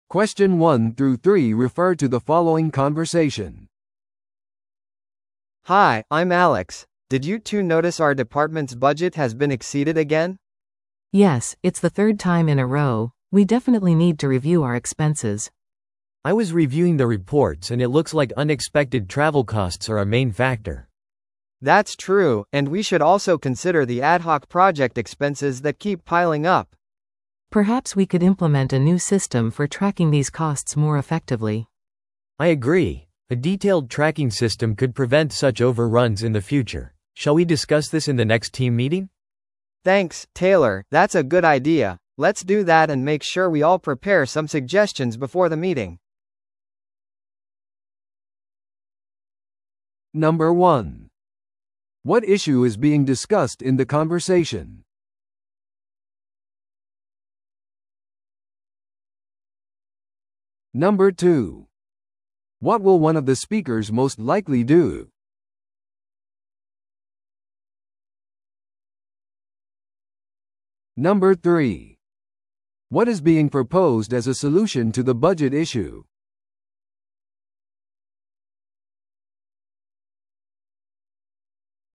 No.1. What issue is being discussed in the conversation?